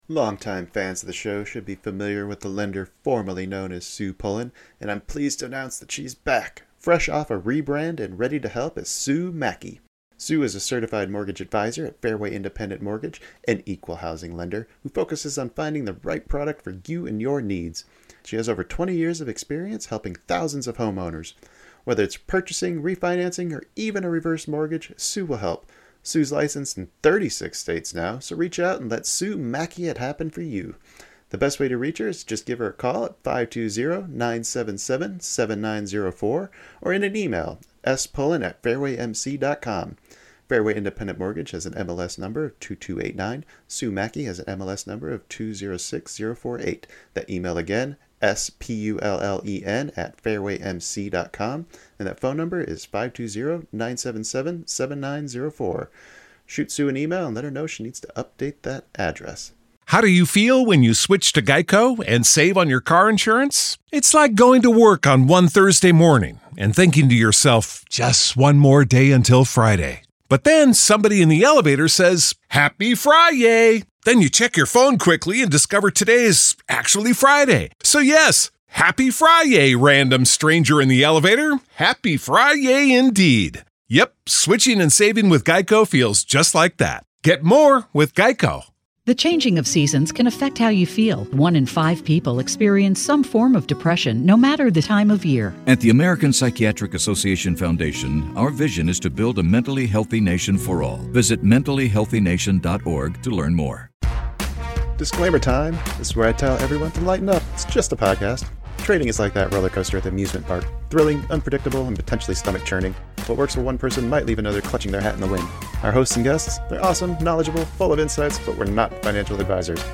Our weekly Round Table episodes are casual conversations about the week's trading lessons, educational content, strategies, and all things trading.